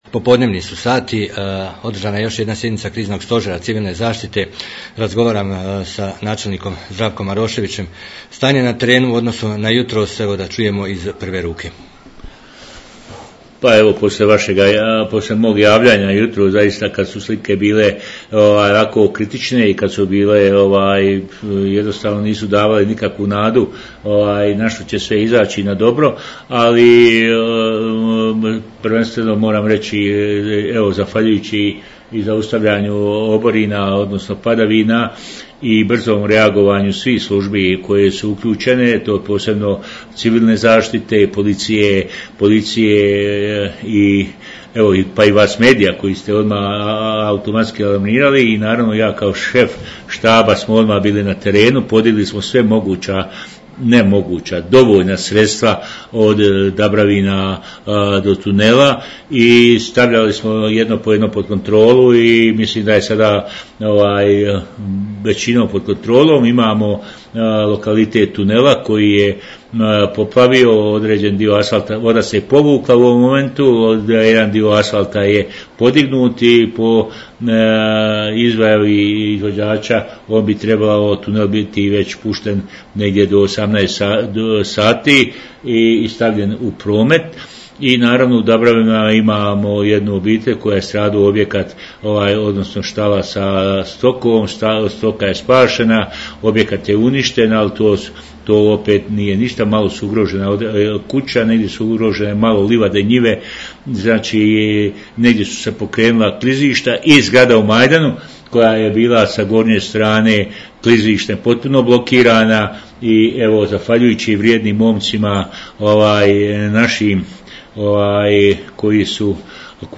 Poslupajte izjavu načelnika Zdravka Maroševića.